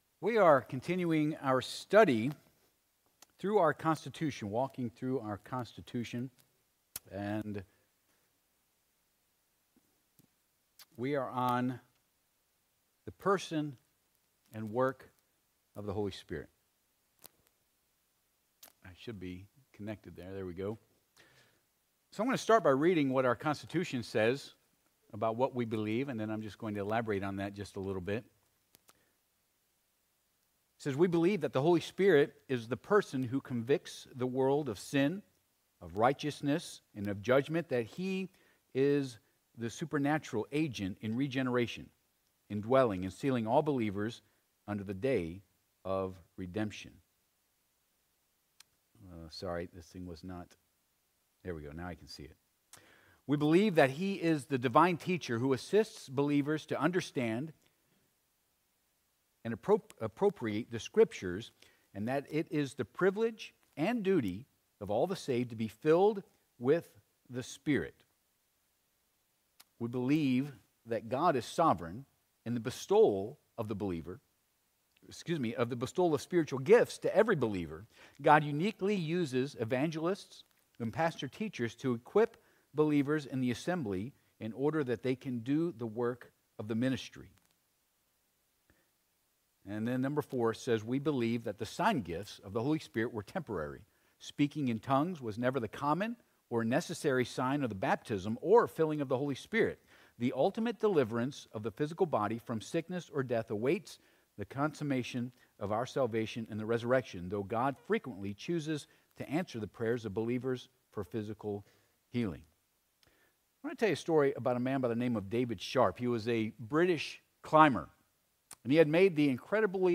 John 16:8 Service Type: Sunday PM « What Makes A Gift Great